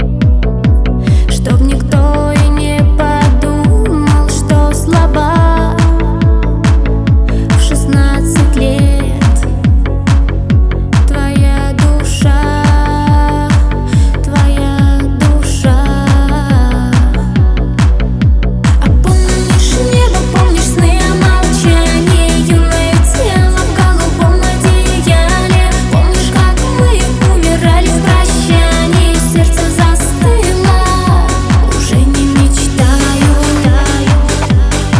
Жанр: Поп музыка / Рок / Русский поп / Русские